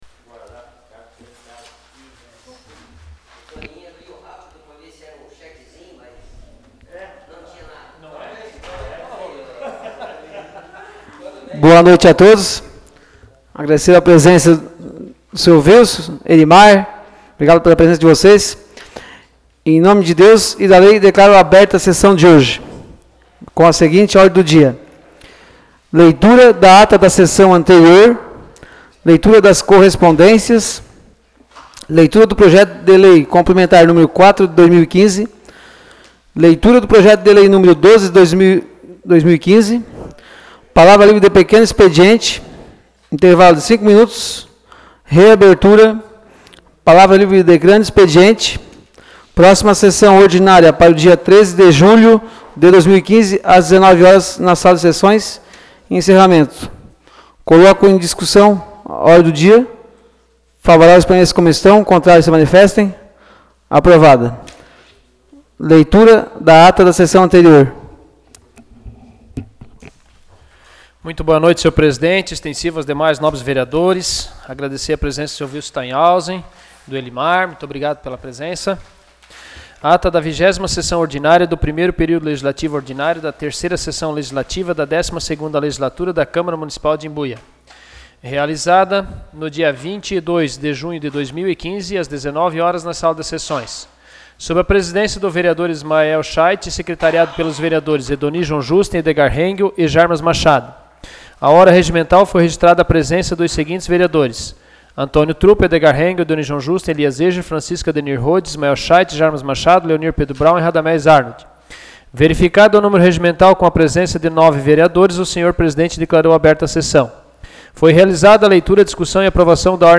Áudio da Sessão Ordinária realizada no dia 06 de julho de 2015.